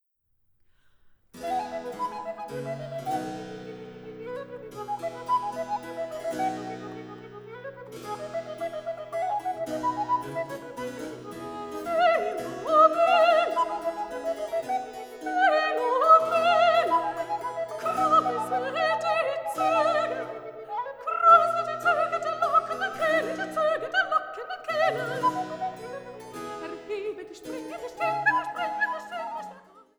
Zwei Arien für Sopran, Altblockflöte und B. c.